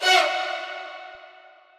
[ACD] - CardiakString Hit.wav